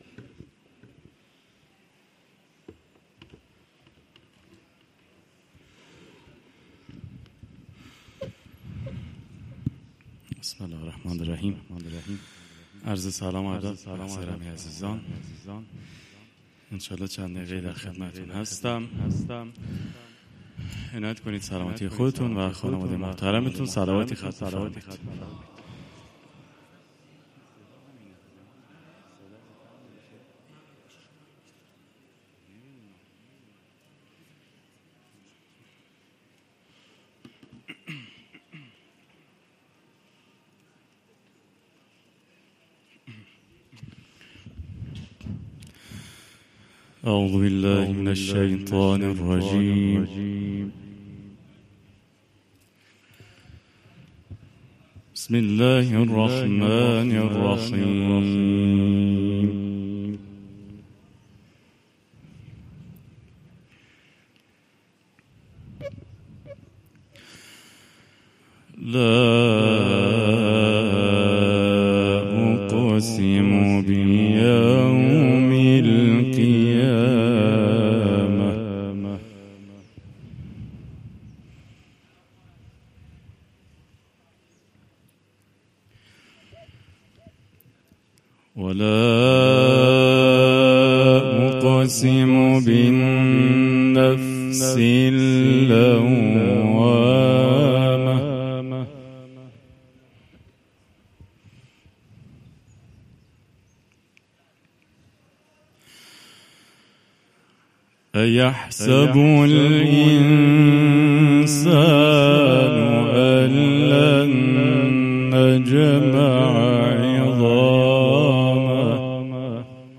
IQNA – Emir Kebir Üniversitesinde Kur’an Pazartesileri programı kapsamında düzenlenen üçüncü Kur’an buluşması gerçekleştirildi.